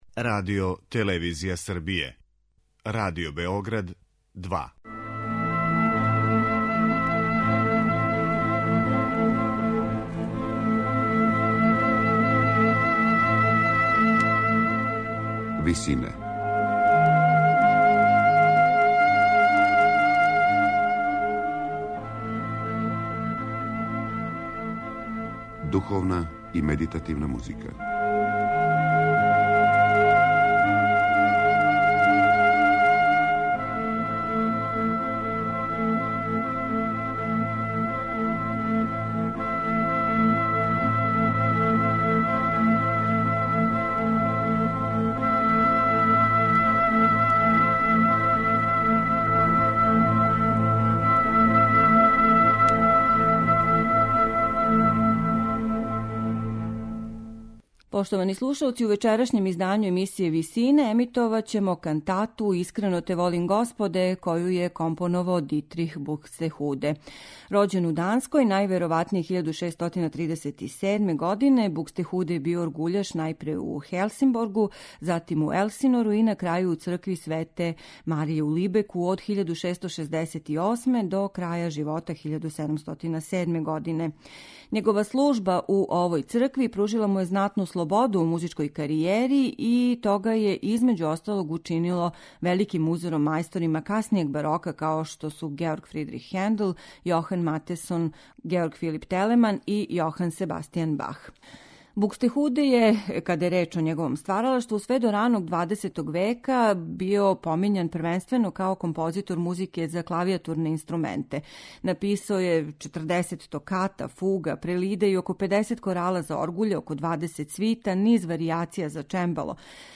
Слушаћете једну од најважнијих барокних кантата
у ВИСИНАМА представљамо медитативне и духовне композиције аутора свих конфесија и епоха.